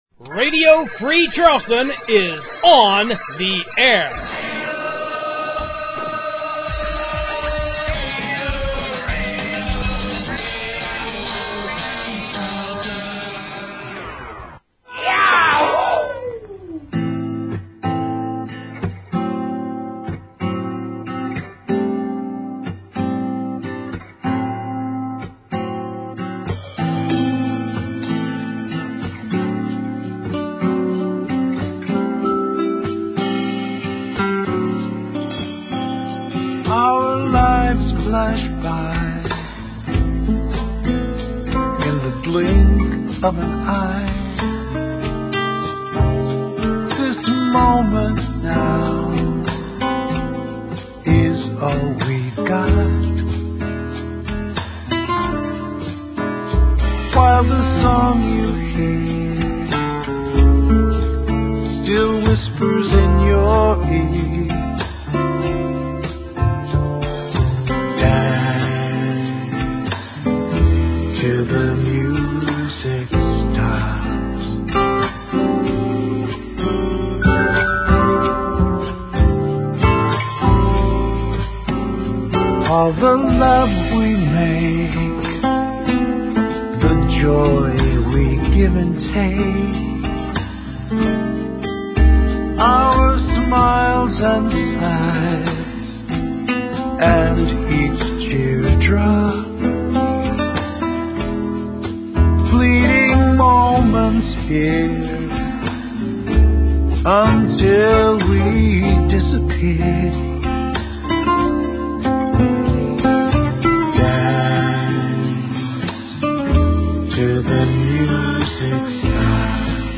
I ad-libbed the announcing with no script this week (as usual) and didn’t realize that it’s a two-day event until I started writing these notes.